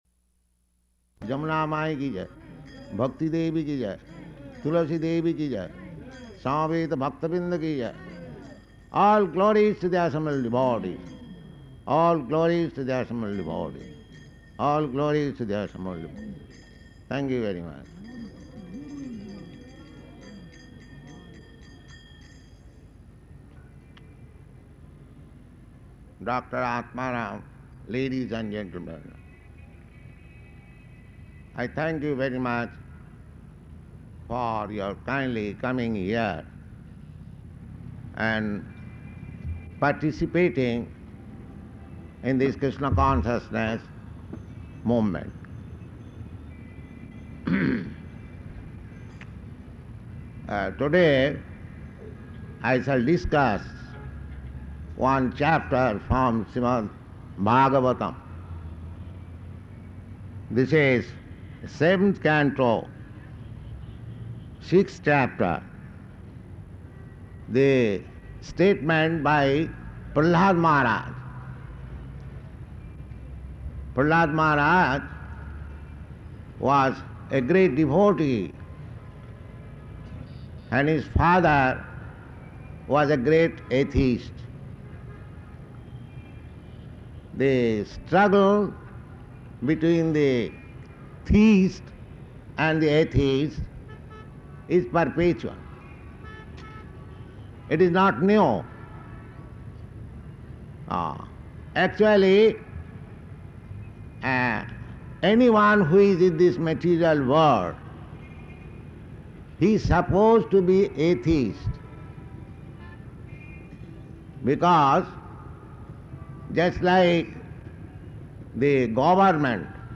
Śrīmad-Bhāgavatam 7.6.1 --:-- --:-- Type: Srimad-Bhagavatam Dated: November 15th 1971 Location: Delhi Audio file: 711115SB-DELHI.mp3 Prabhupāda: [ prema-dhvani ] Thank you very much.